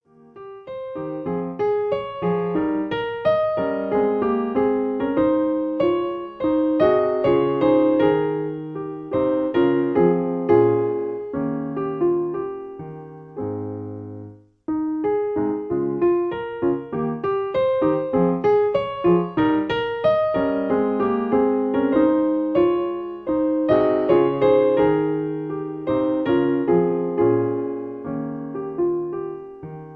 Piano accompaniment. In A-flat